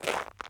Ice Footstep 21.ogg